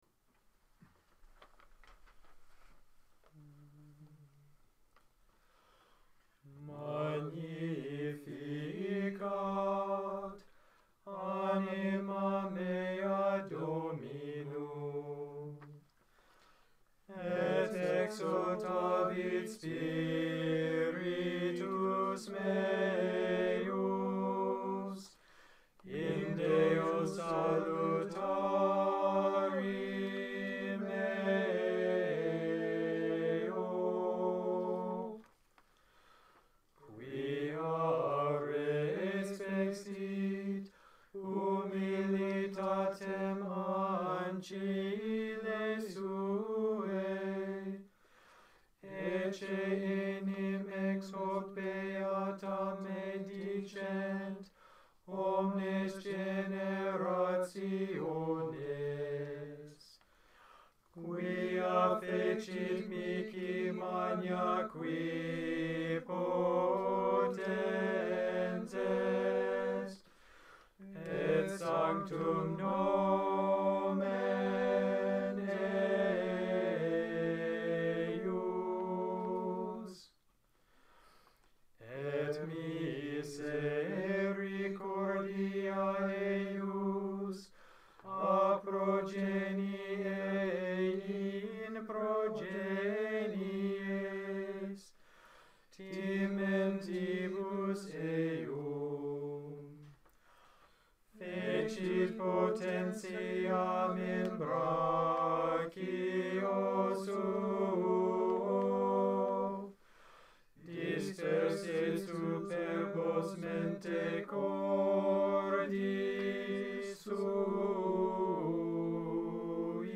Gregorian, Catholic Chant Magnificat
The recording is an alternation between the monastery chant, and a simplified (2-part) harmonization of Ciro Grassi's setting.